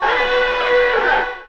VEC3 FX Alarm 10.wav